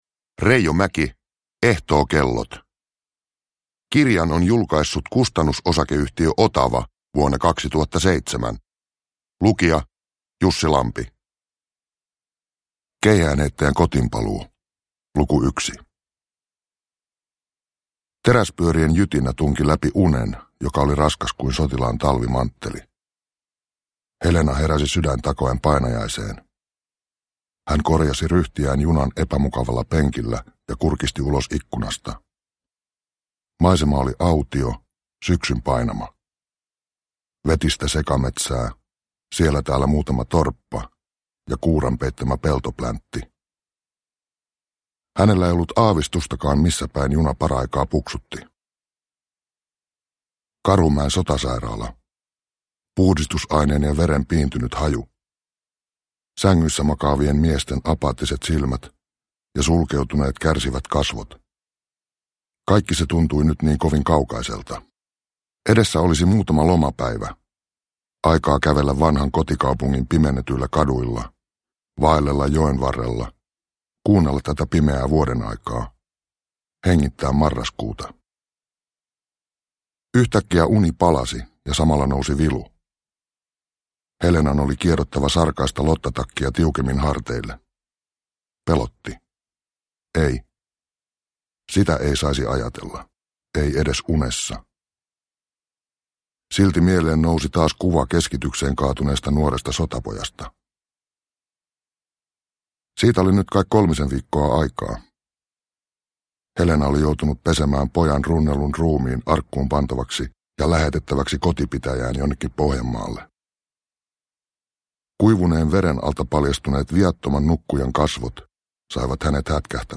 Ehtookellot – Ljudbok – Laddas ner